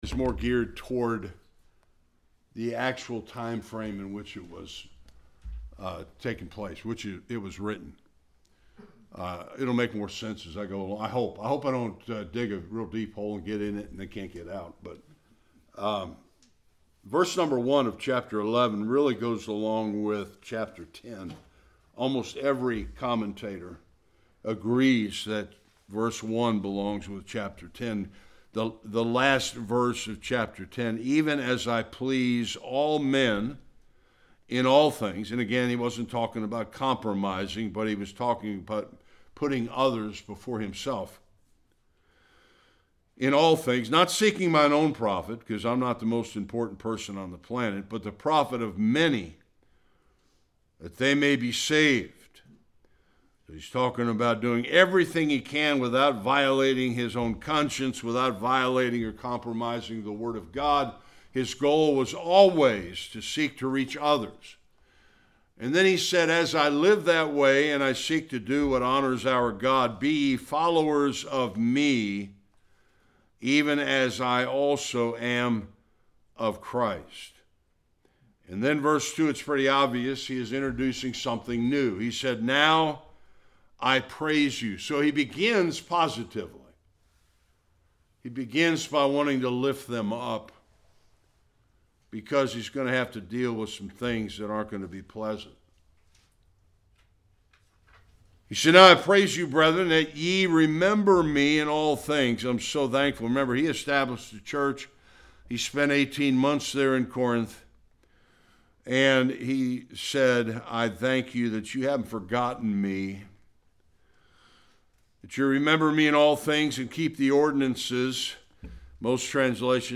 2-16 Service Type: Sunday Worship God’s Word is clear concerning the roles of men and woman in the church.